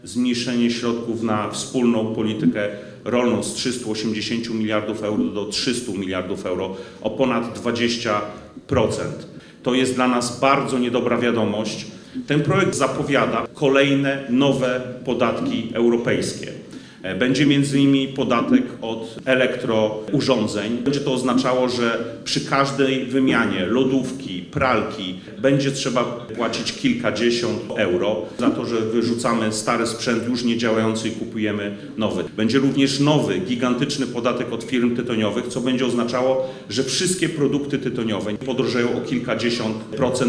Polskie finanse, klimat i przyszłość Europy – to tylko niektóre tematy omawiane podczas sobotniej konferencji Europejskich Konserwatystów i Reformatorów w Białogardzie.
Największe emocje wzbudziło jednak wystąpienie europosła Adama Bielana, który ostrzegł przed konsekwencjami projektowanego unijnego budżetu.